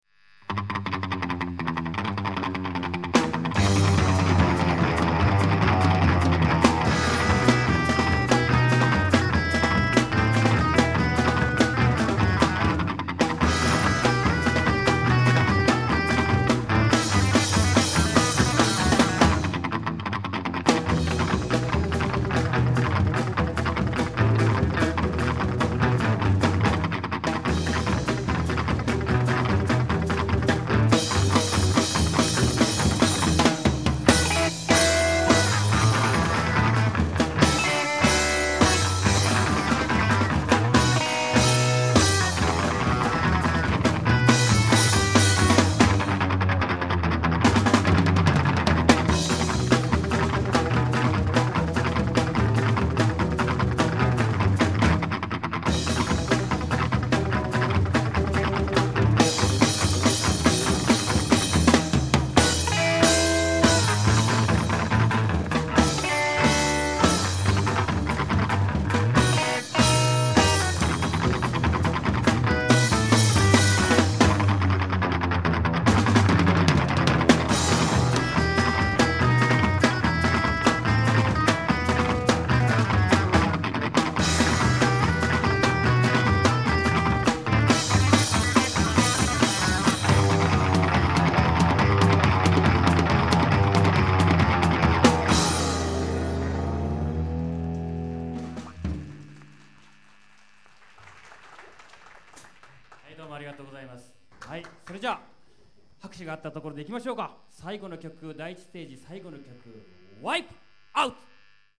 Reproducing the Summer of 1965 Japan concerts.